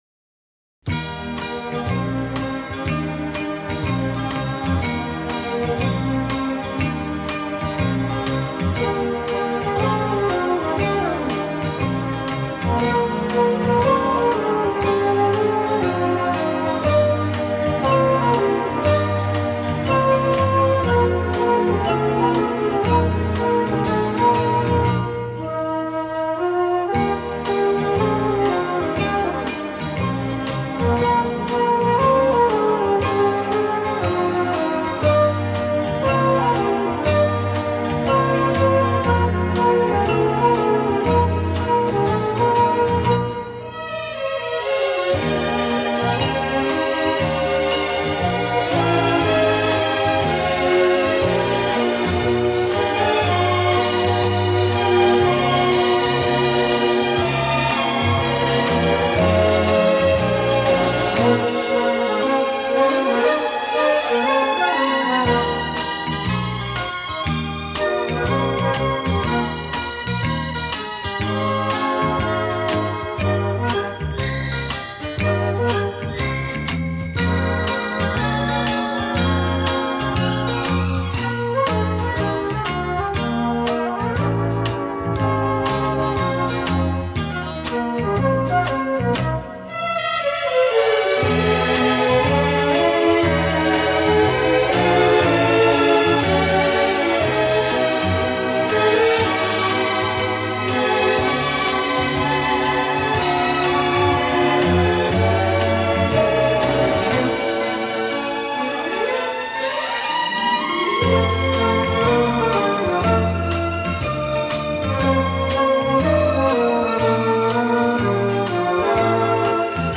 Genre:Easy Listening, Instrumental